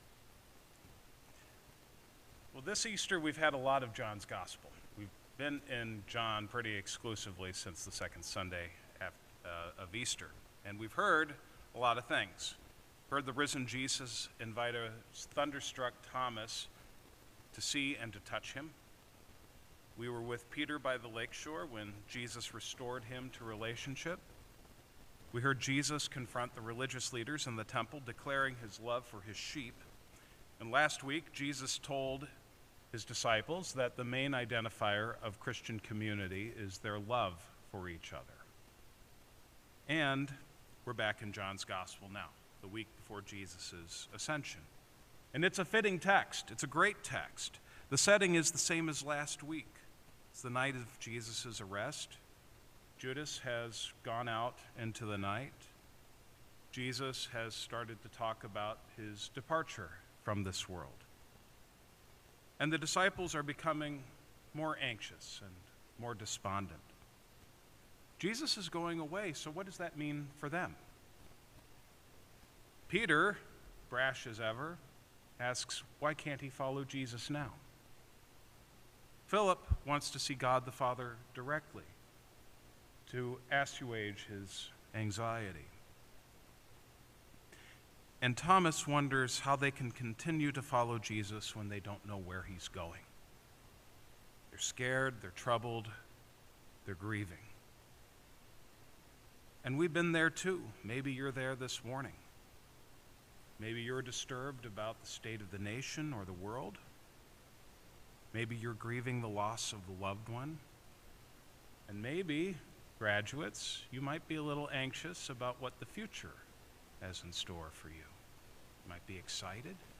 Sermons | Shalom Lutheran Church